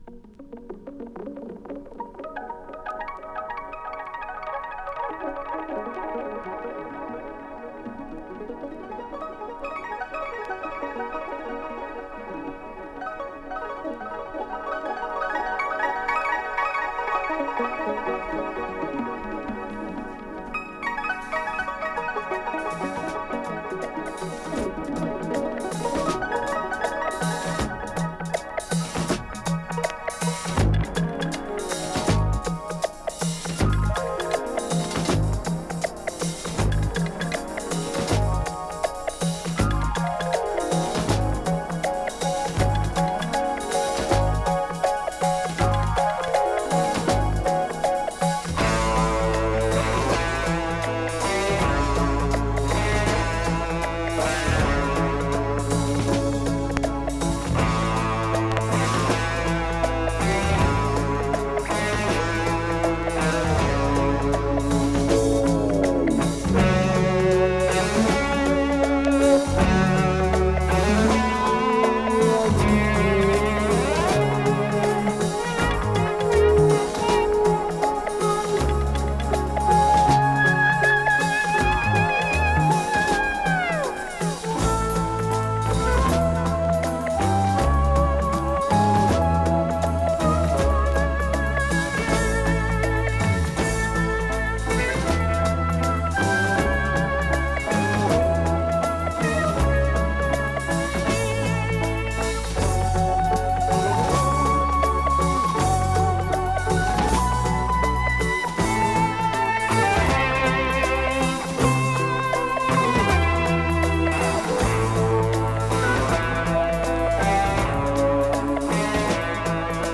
Norwegian Synth Rock!
【COSMIC】【SYNTH POP】